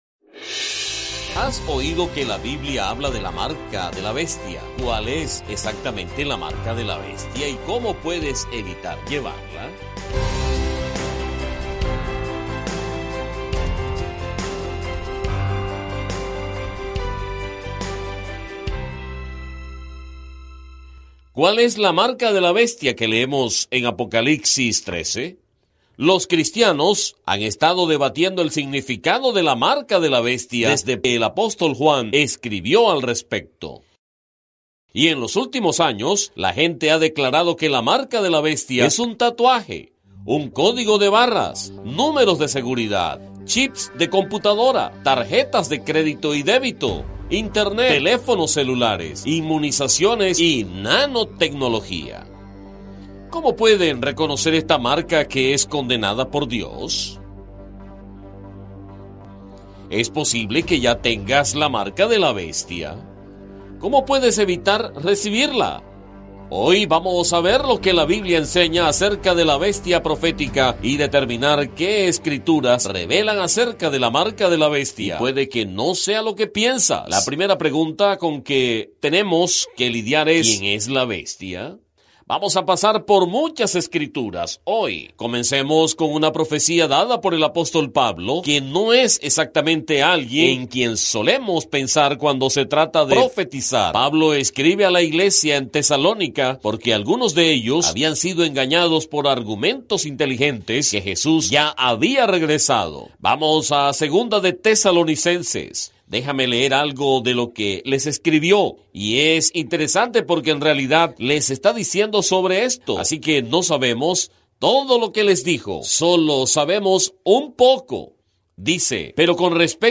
programa de televisión